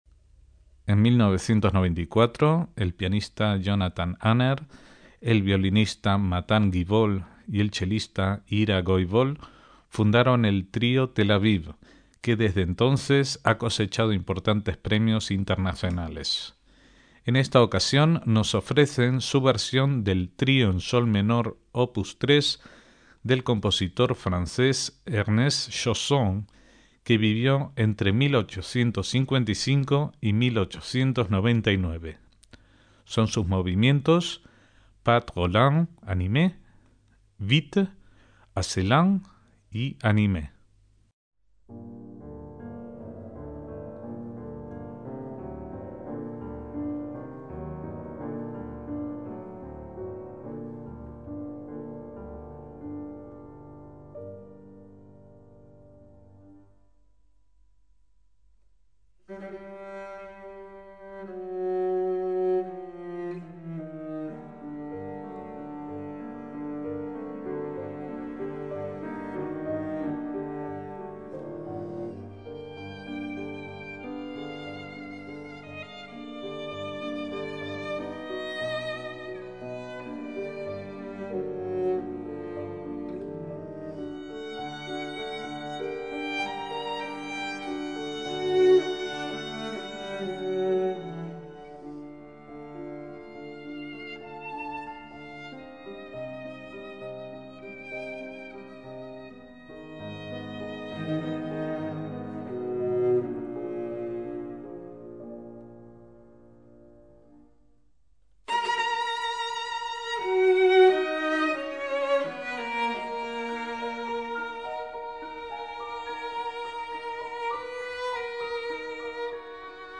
MÚSICA CLÁSICA
conjunto de cámara
violín
chelo
piano